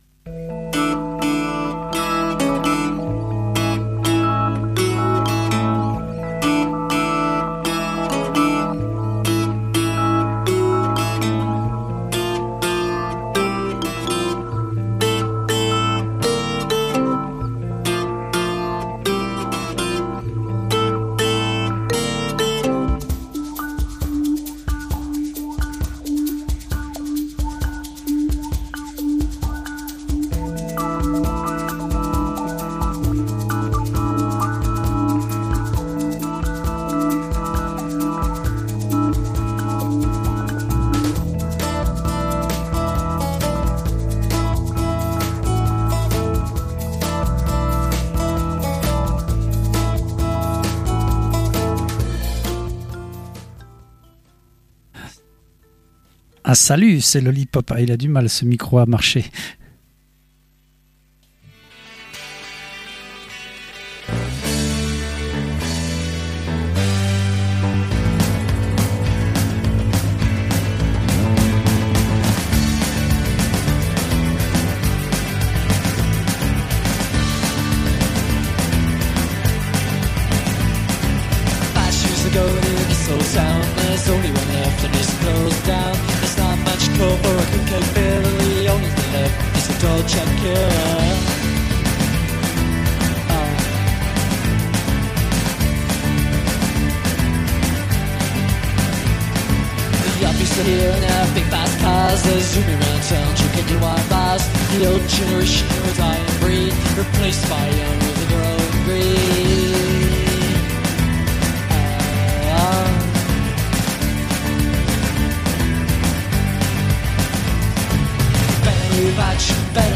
Toutes les nouveautés pop et indie pop du moment